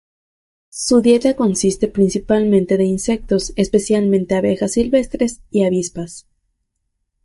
es‧pe‧cial‧men‧te
/esˌpeθjalˈmente/